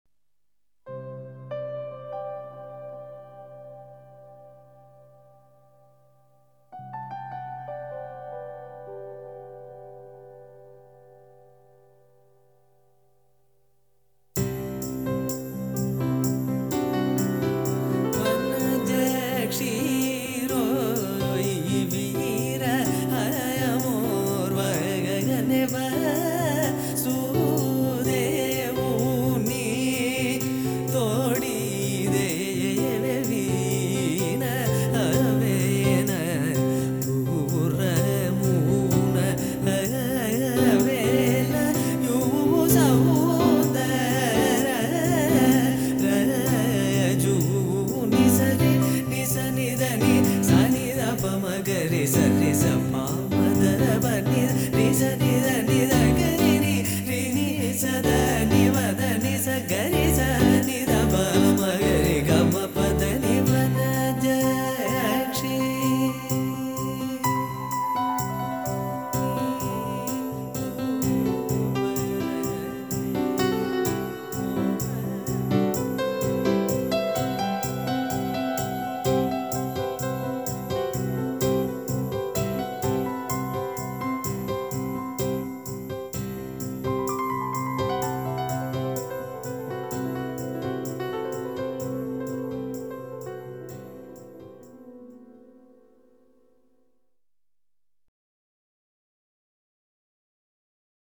Recorded Panchathan Record Inn
Instrumental